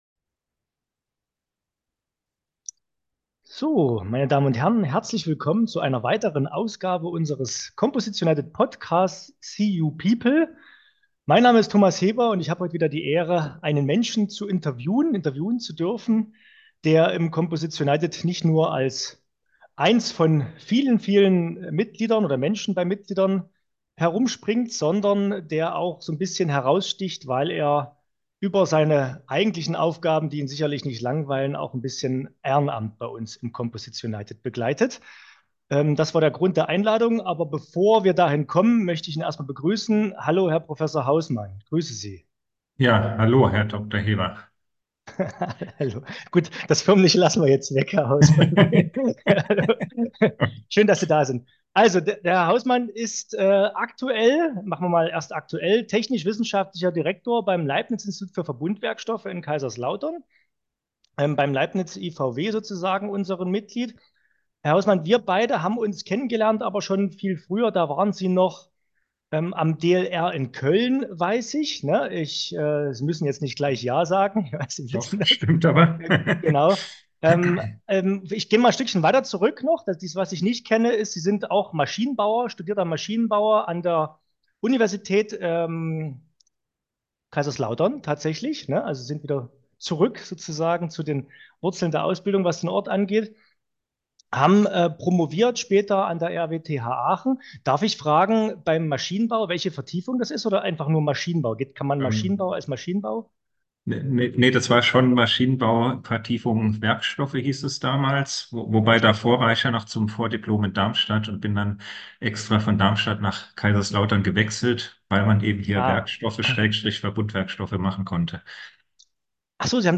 #44 Im Interview